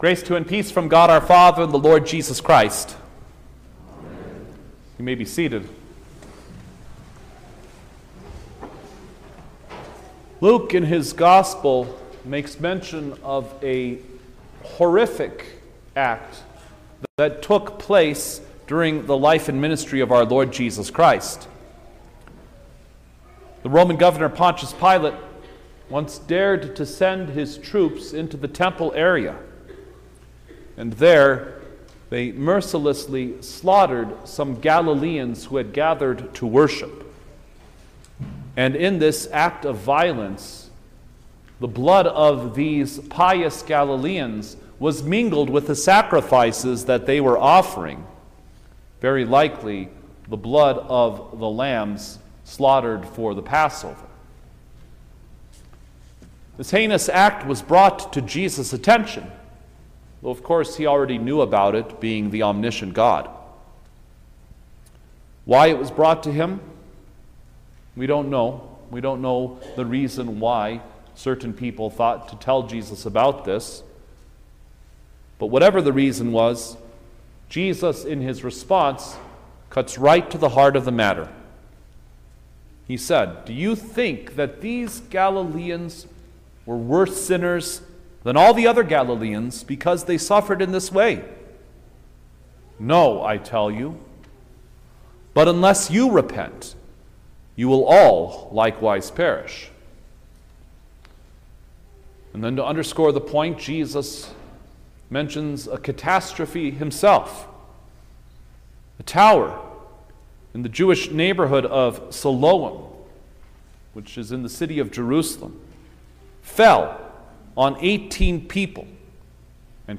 March-5_2025_Ash-Wednesday_Sermon-Stereo.mp3